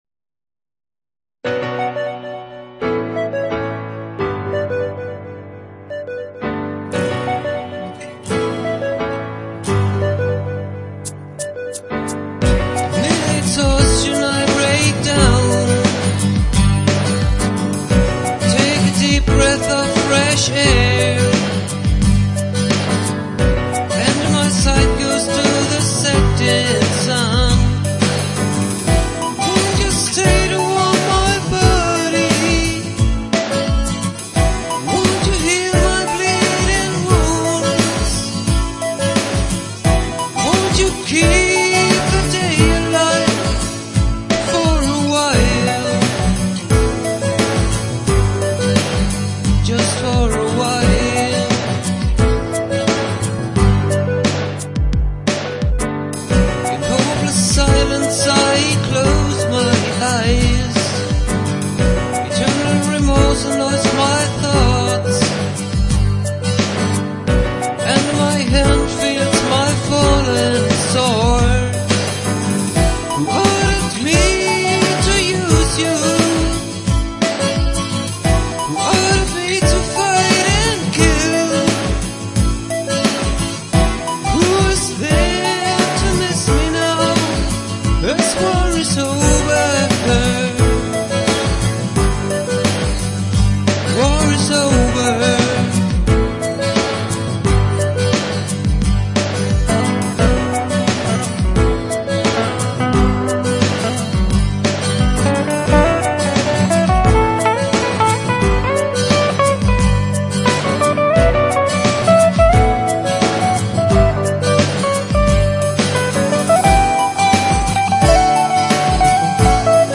TypLP (Studio Recording)